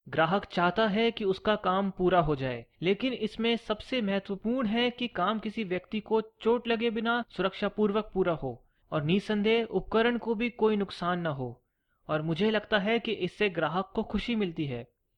Medium Base, Smooth, Pleasant, Soft &#61558
My Dialect is Hindi-Urdu.
Sprechprobe: Industrie (Muttersprache):